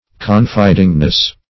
Meaning of confidingness. confidingness synonyms, pronunciation, spelling and more from Free Dictionary.
-- Con*fid"ing*ness , n. [1913 Webster]